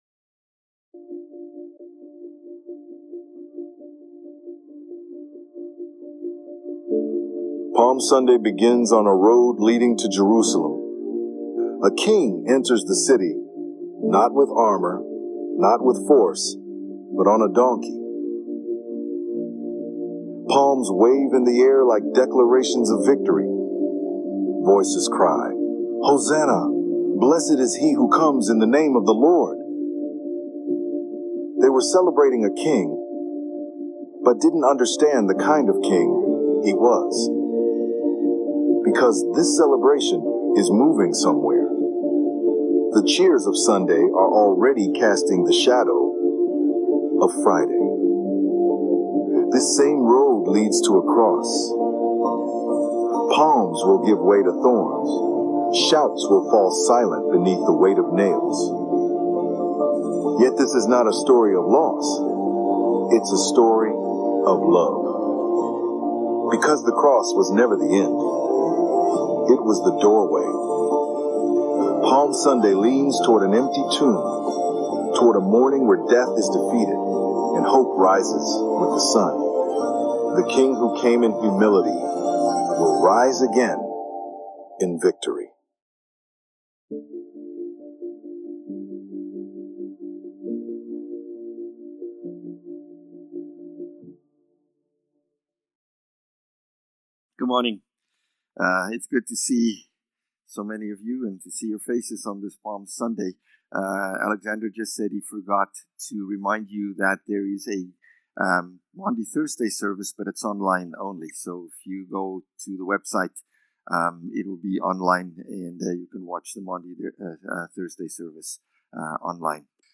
March-29-Palm-Sunday-Sermon.mp3